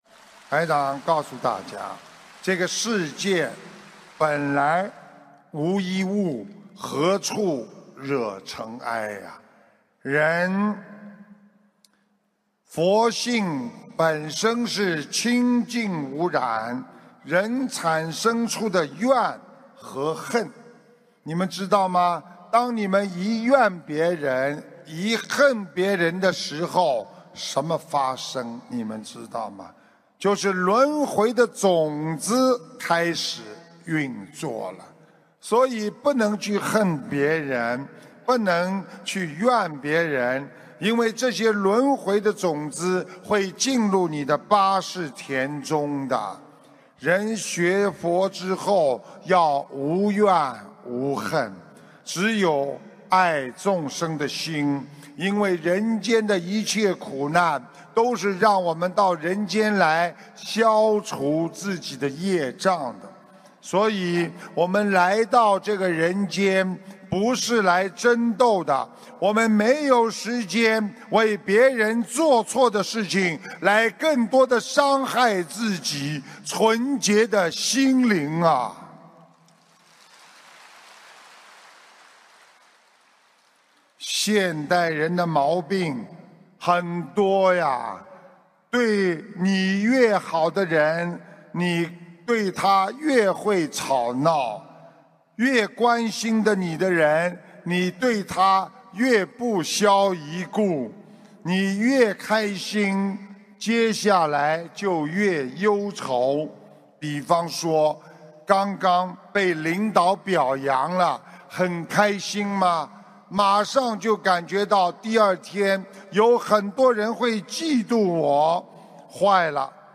- 法会节选 心灵净土